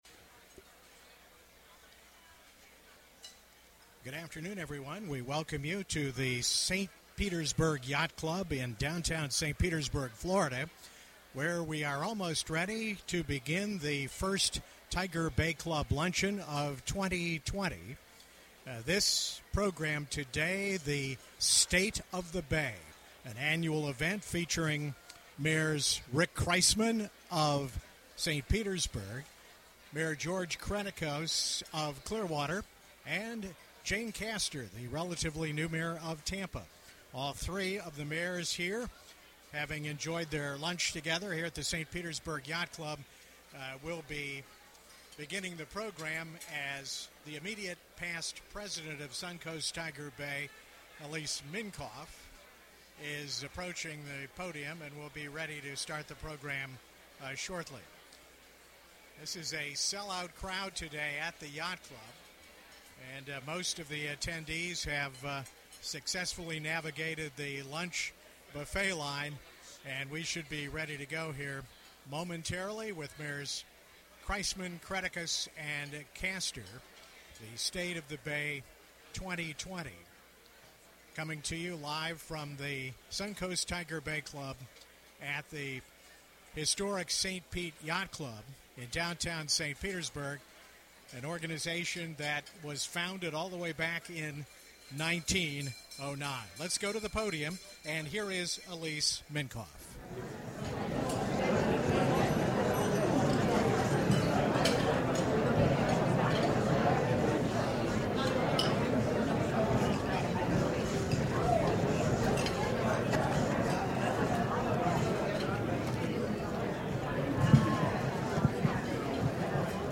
Area mayors take questions in the "Tiger's Den."